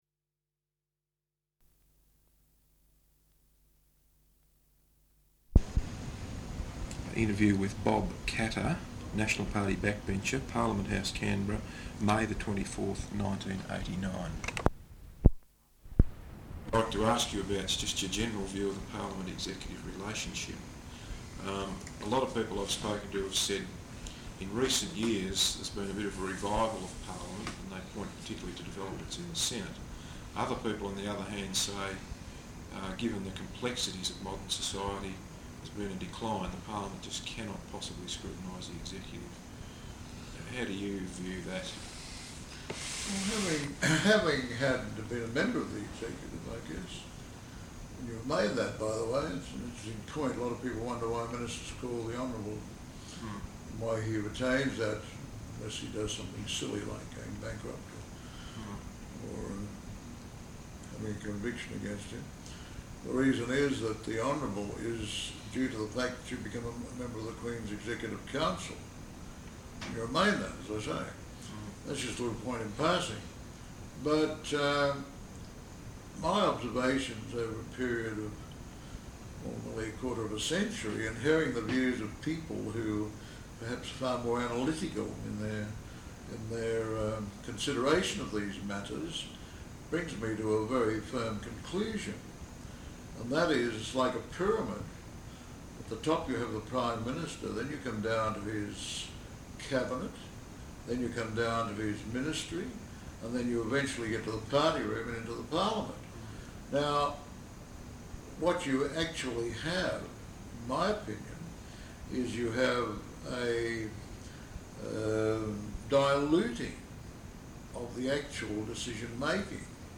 Interview with Bob Katter, National Party Backbencher, Parliament House, Canberra May 24th, 1989.